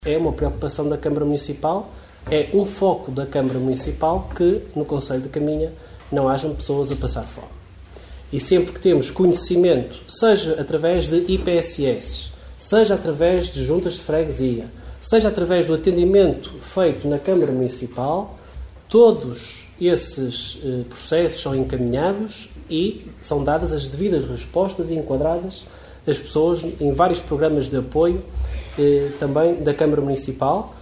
O alerta foi deixado pela vereadora da Coligação O Concelho em Primeiro, Liliana Silva, no período antes da ordem do dia da reunião de Câmara realizada no passado dia 21 de fevereiro.
Em resposta ao alerta deixado pela vereadora, o presidente da Câmara referiu que a questão da carência de bens alimentares é uma preocupação do executivo.